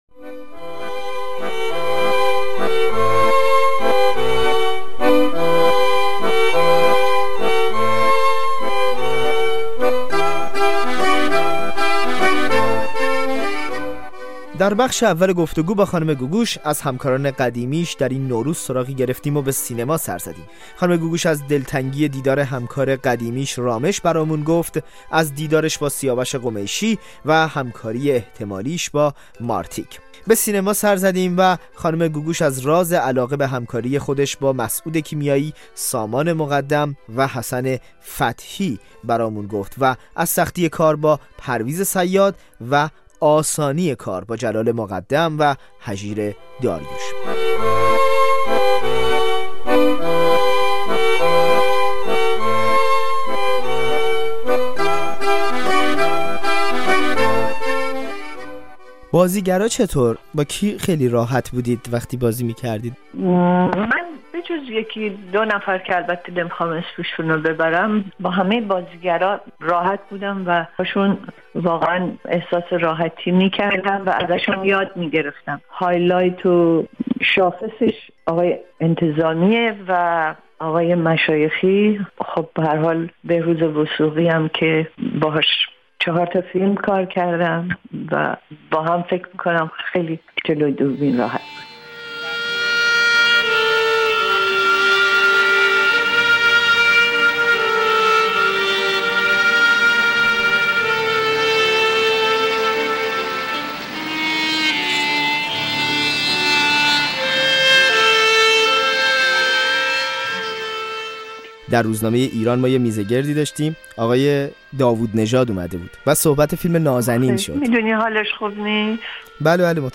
در قسمت دوم این گفت‌وگو، خانم گوگوش از تاثیرگذارترین هنرپیشه‌هایی که با او همبازی بوده‌اند گفته است. او همچنین به تاثیر ویگن بر موسیقی‌اش اشاره می‌کند، به انتقادها از اجرای ترانه «مرغ سحر» پاسخ می دهد و همچنین به برنامه «استیج» و «آکادمی» می‌پردازد.